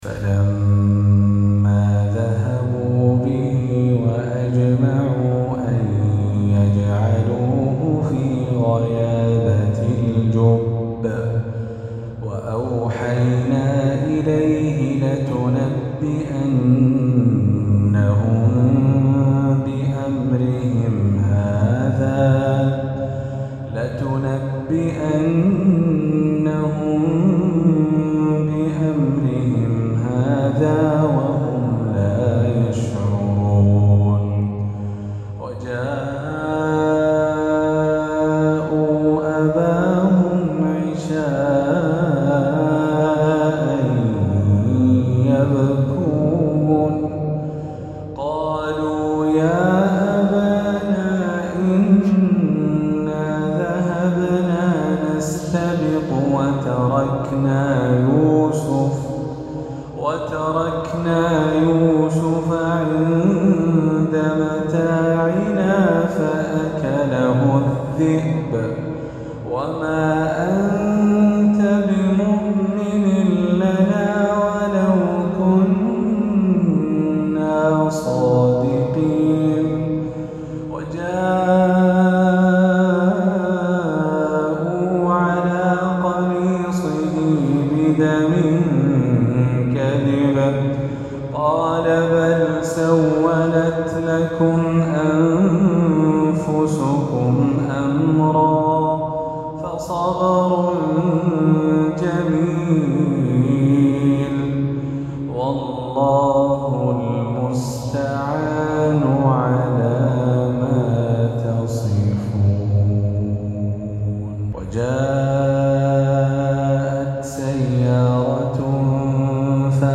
عشائية بترتيل بديع